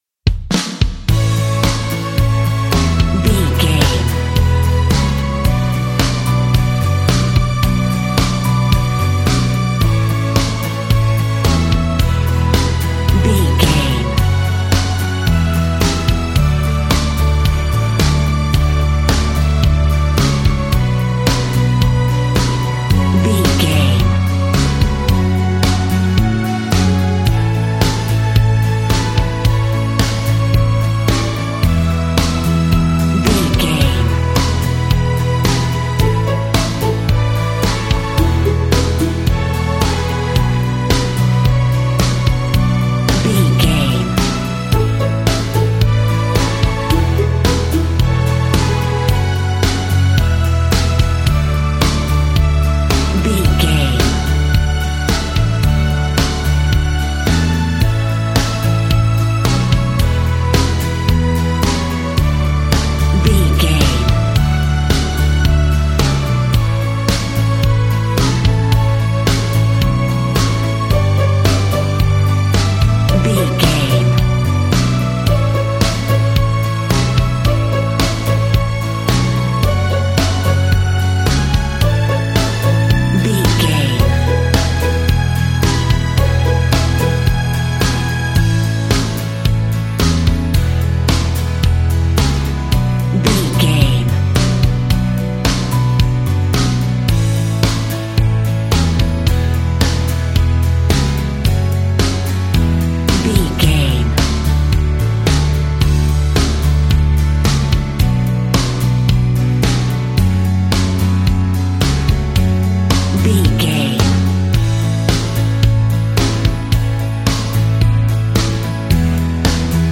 Ionian/Major
calm
melancholic
smooth
soft
uplifting
electric guitar
bass guitar
drums
strings
pop rock
indie pop
organ